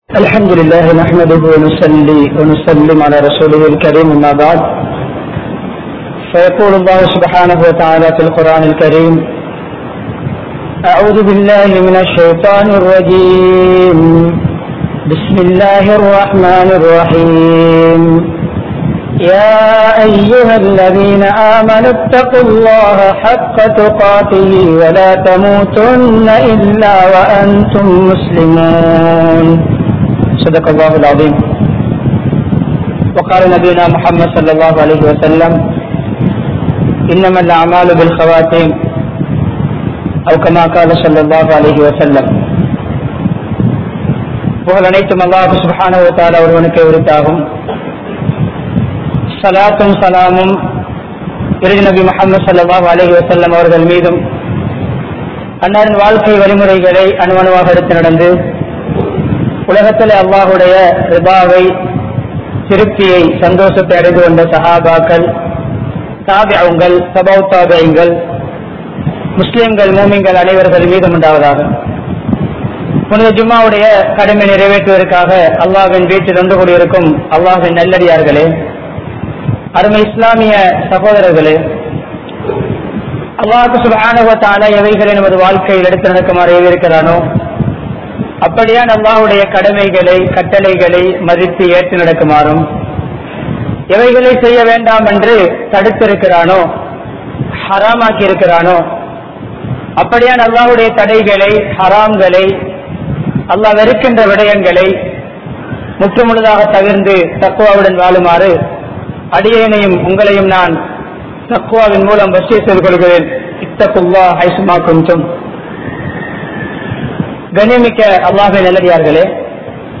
Nalla Maranamum Theeya Maranamum(நல்ல மரணமும் தீய மரணமும்) | Audio Bayans | All Ceylon Muslim Youth Community | Addalaichenai
Colombo 12, Aluthkade, Muhiyadeen Jumua Masjidh